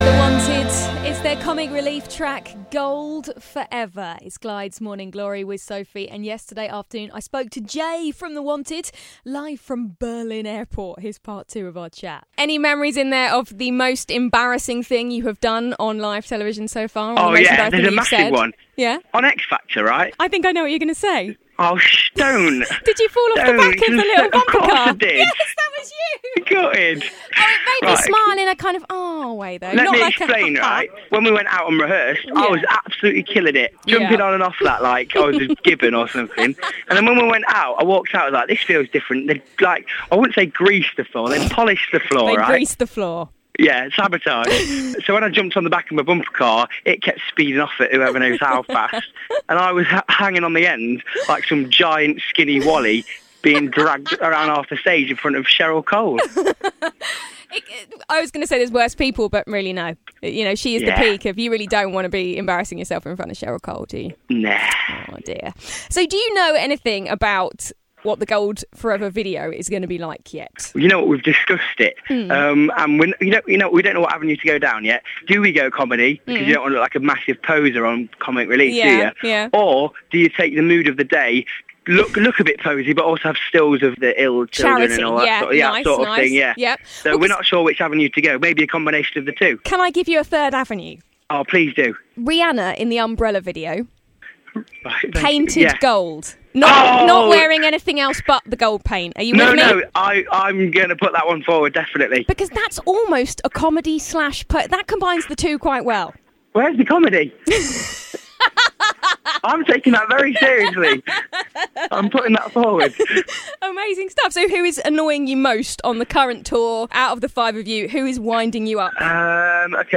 Glide's Morning Glory interview with Jay from The Wanted Part 2